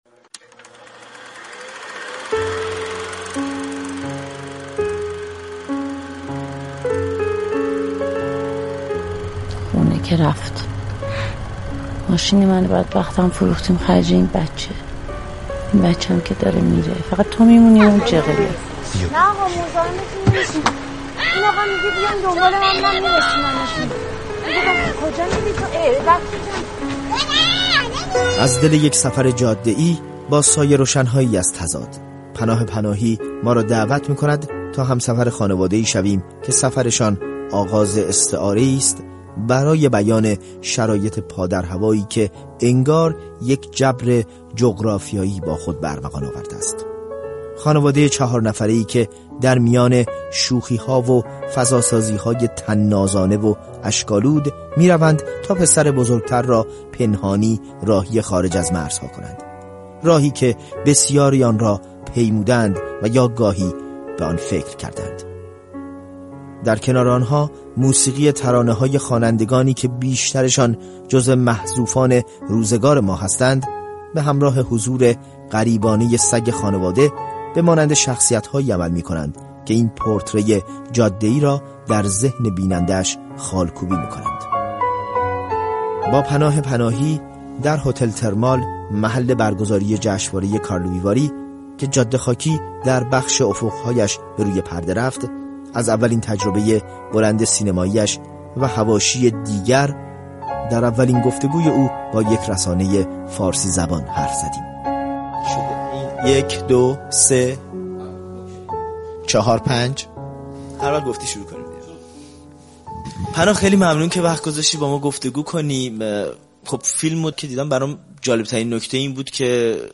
گفت‌وگوی اختصاصی